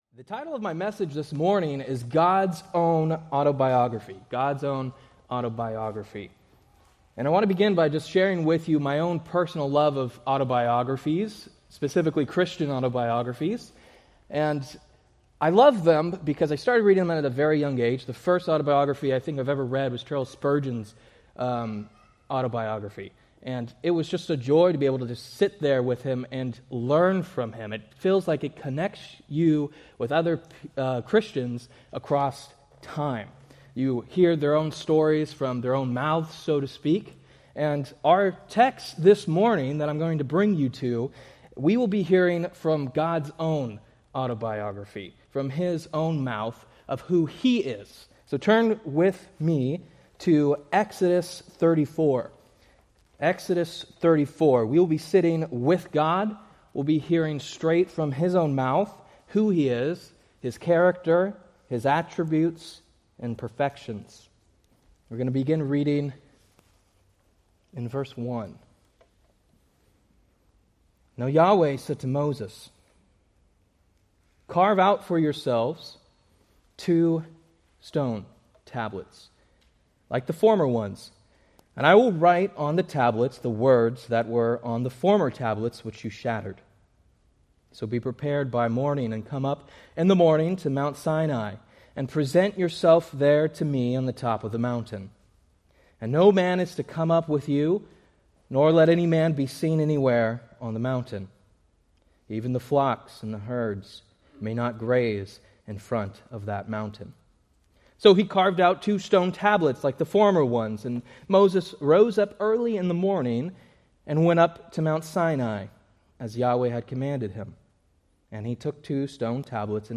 Date: Jan 4, 2026 Series: Various Sunday School Grouping: Sunday School (Adult) More: Download MP3